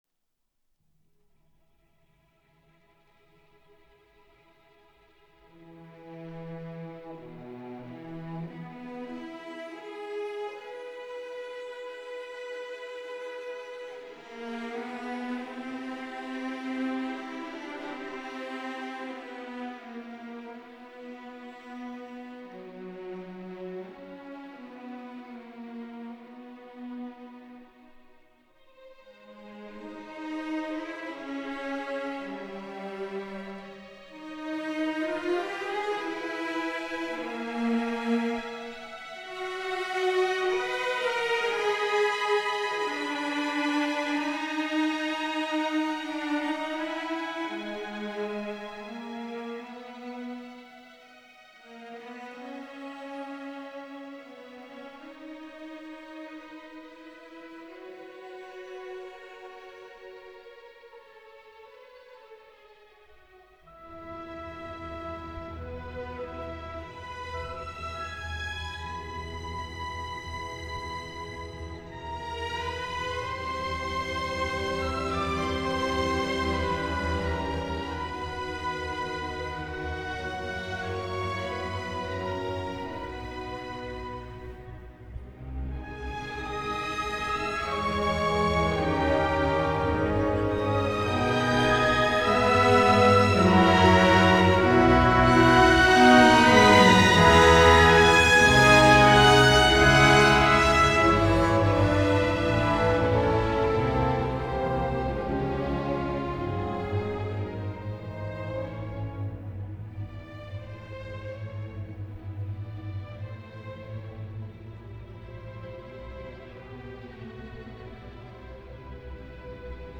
Recorded at – Kingsway Hall - November 1960